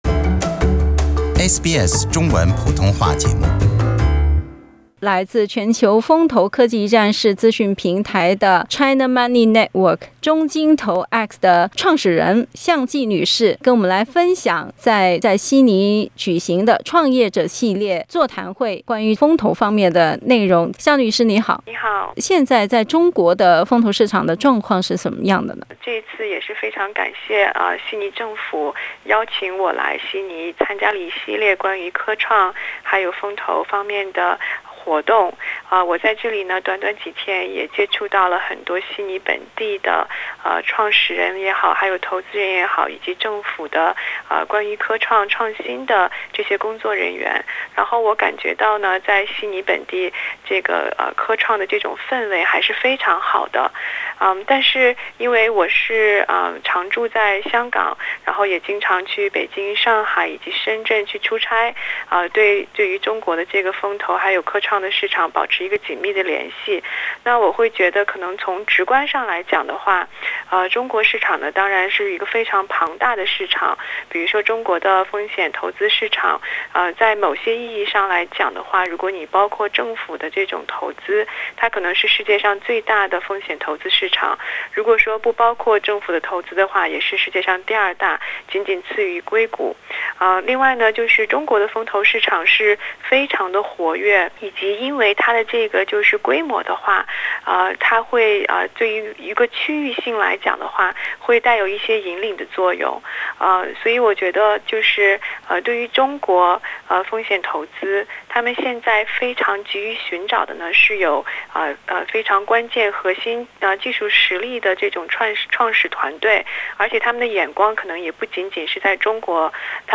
下面我们一起来了解中澳风险投资领域合作的可能性。 （本节目为嘉宾观点，不代表本台立场。）